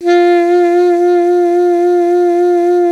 55AF-SAX06-F.wav